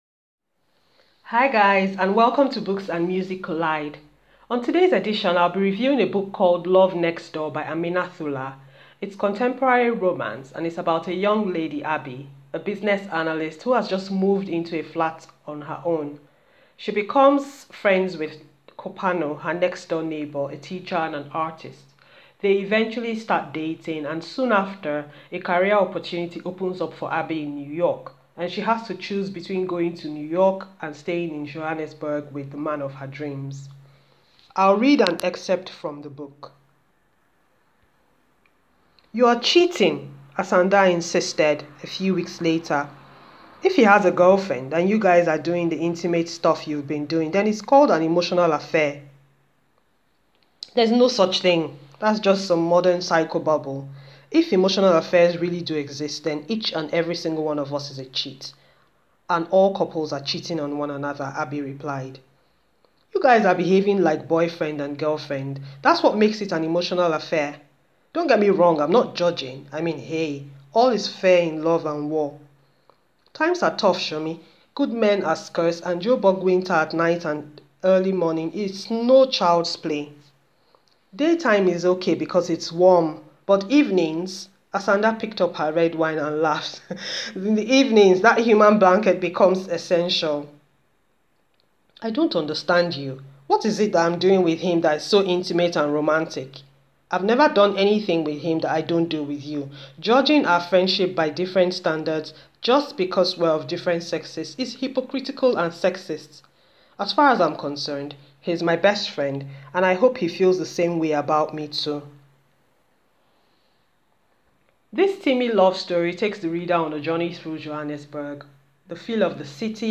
An audio book review of Love Next Door by Amina Thula.